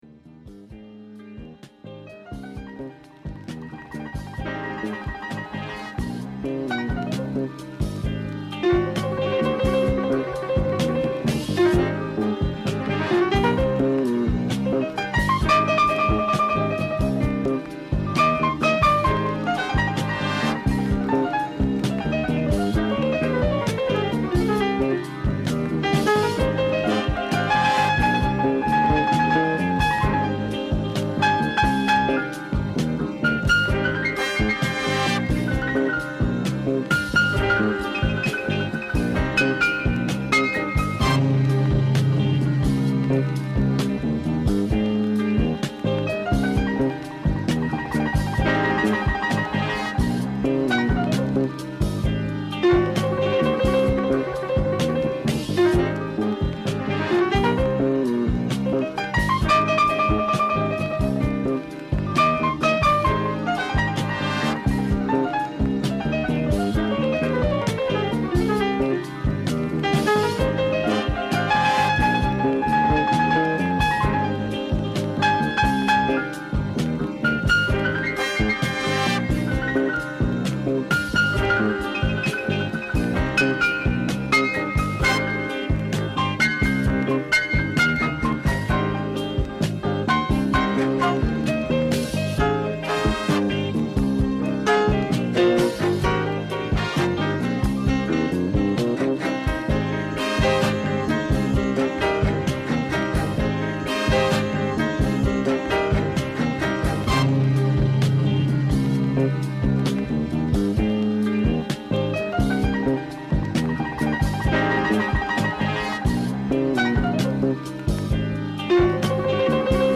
卓越したテクニック／スキルが独特のエッセンスとなり、抜群のMixに仕上がっています！
Mellow Groove